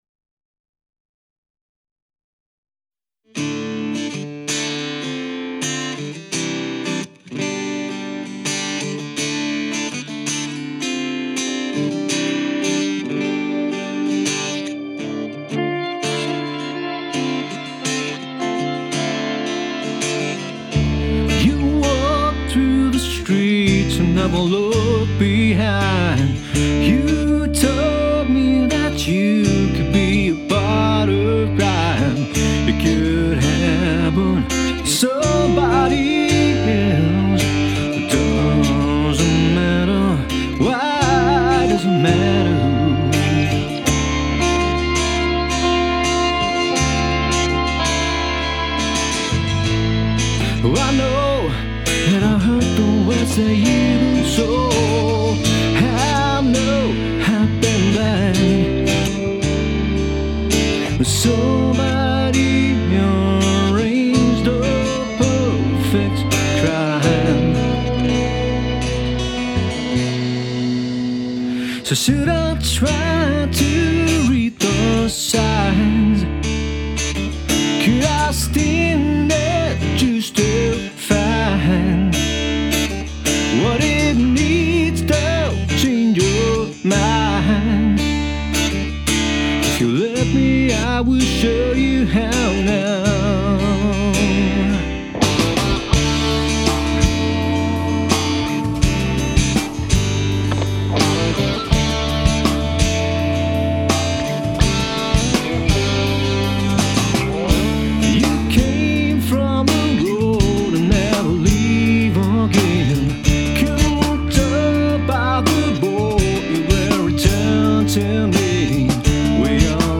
in einer dynamisch aufsteigenden Spannungskurve